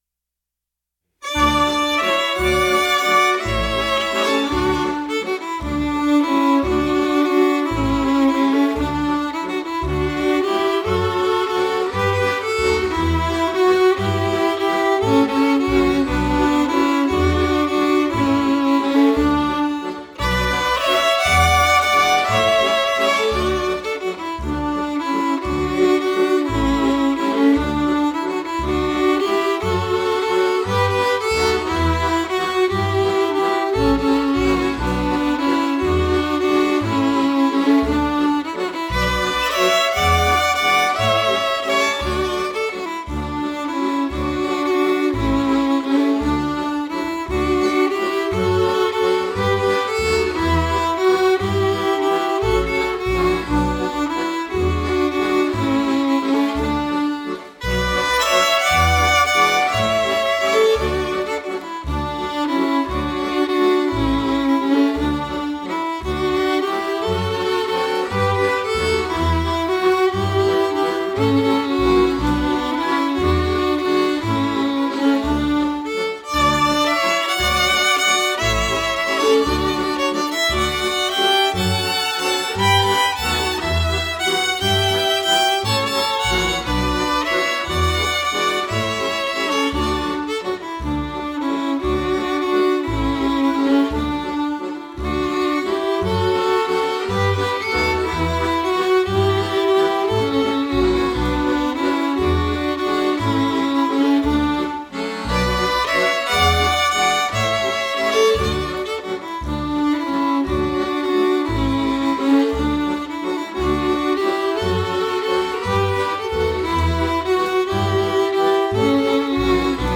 tam-na-hori-zimota-podklad.mp3